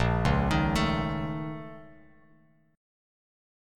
A#mM11 chord